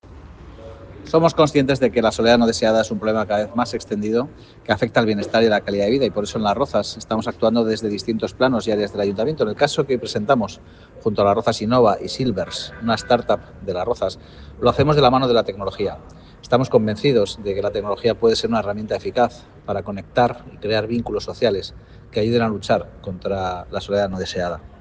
Jose-de-la-Uz-alcalde-Las-Rozas_pte-Las-Rozas-Innova.ogg